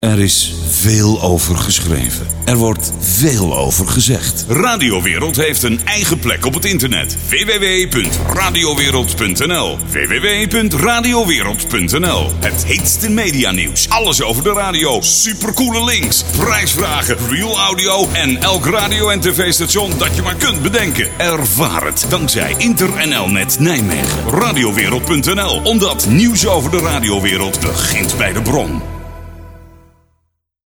hier een radio commercial die in 2001 een week lang op alle regionale commerciële radiostations van Nederland te horen was.
radiowereld-reclame.mp3